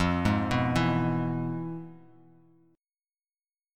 FmM7b5 Chord
Listen to FmM7b5 strummed